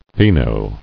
[fi·no]